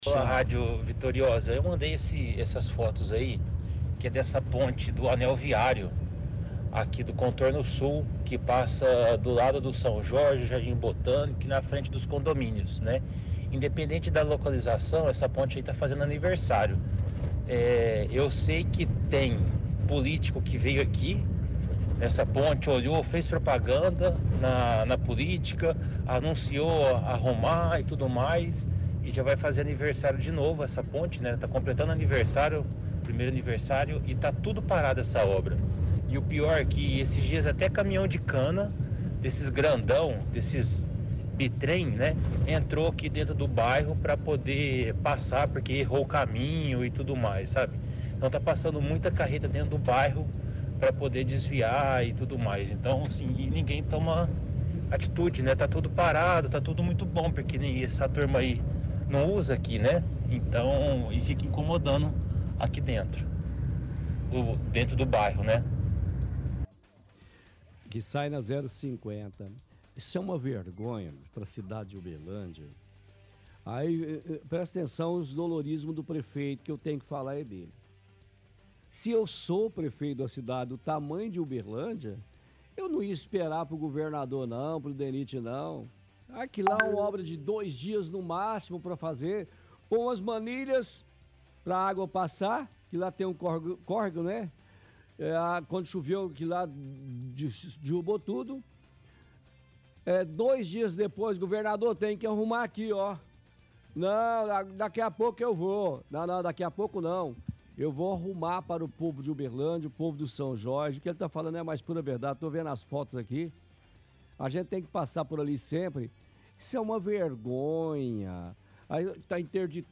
– Ouvinte reclama que obra do anel viário sul não foi feita e está prejudicando o trânsito.